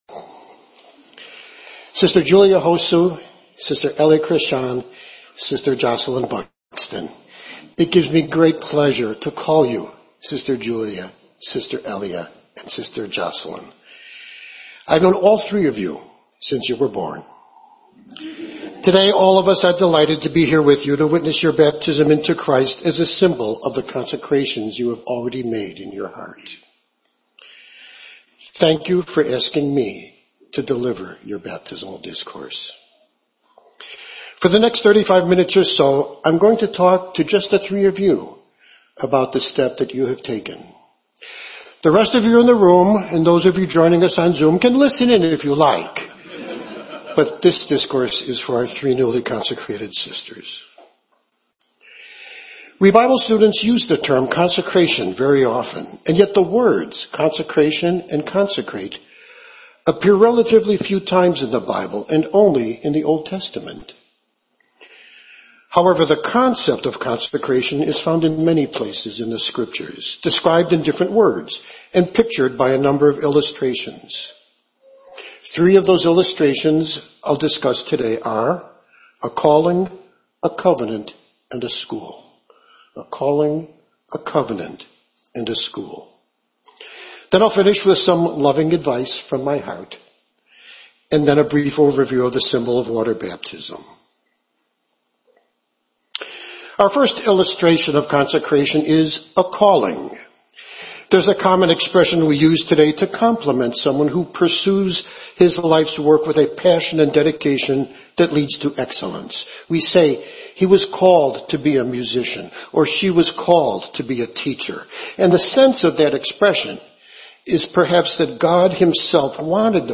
Series: 2025 Chicago Memorial Day Convention